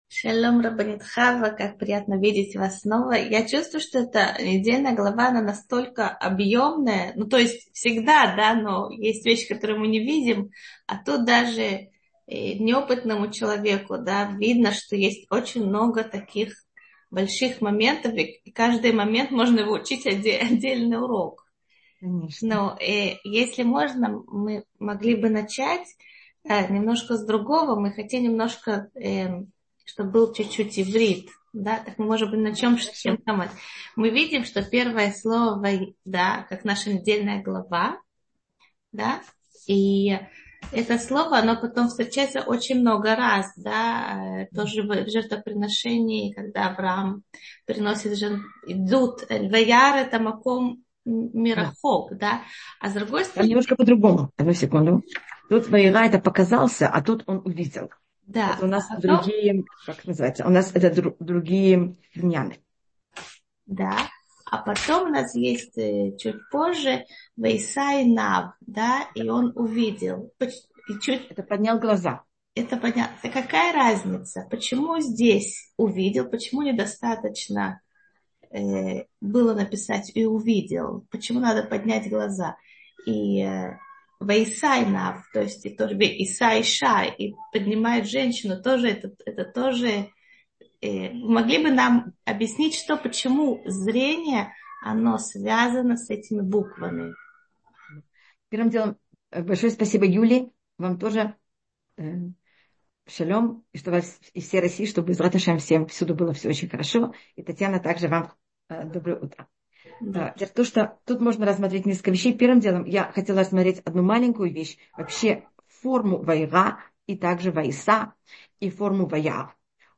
Утренний зум «в гостях у Толдот». Теория света: как в слове «свет» — «ор» закодирована его физическая и философская сущность. Какими критериями пользоваться в испытаниях и экстремальных ситуациях?